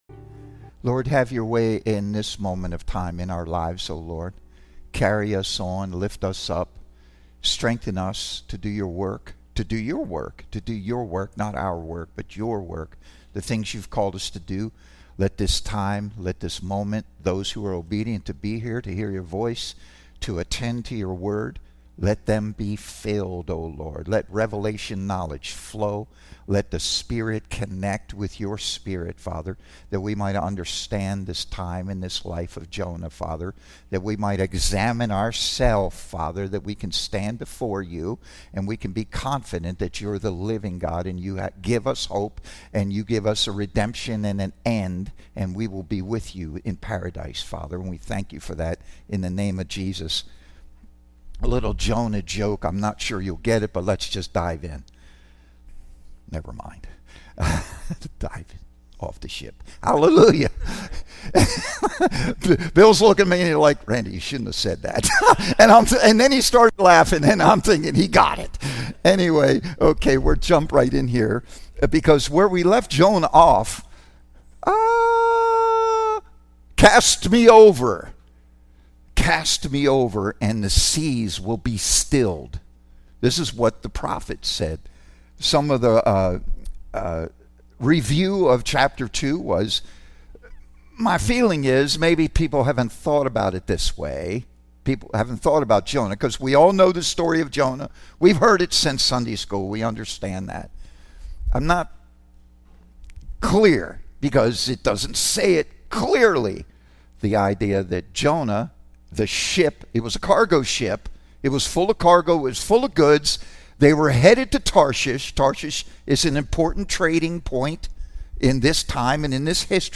Teaching Service